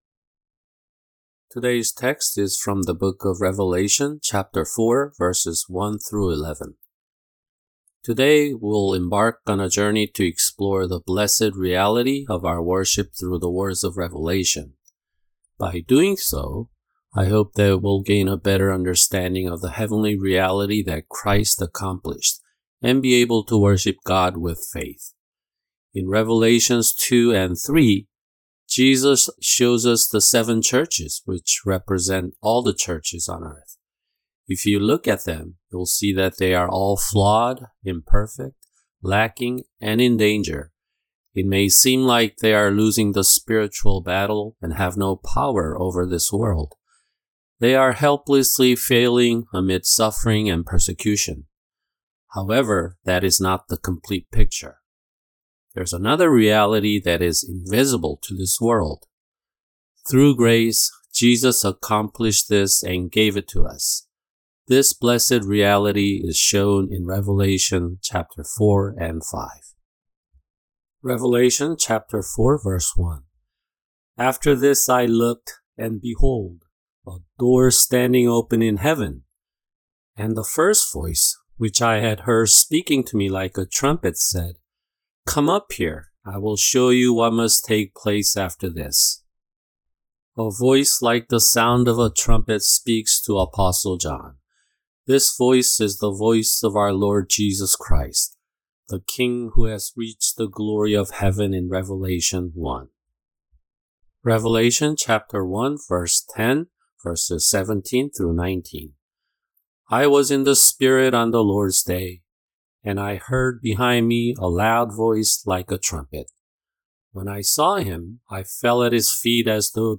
[주일 설교] 계시록 4:1-11(3)